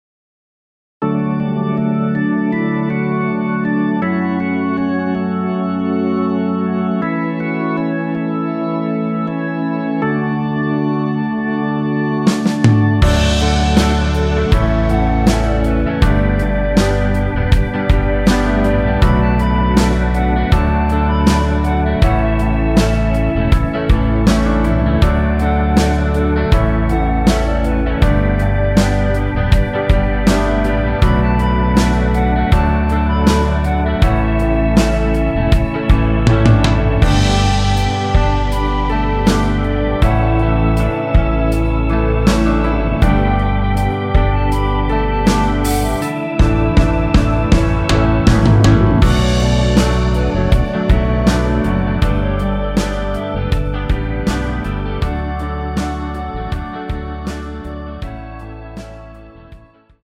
원키에서 (-1)내린 멜로디 포함된 MR 입니다.
◈ 곡명 옆 (-1)은 반음 내림, (+1)은 반음 올림 입니다.
앞부분30초, 뒷부분30초씩 편집해서 올려 드리고 있습니다.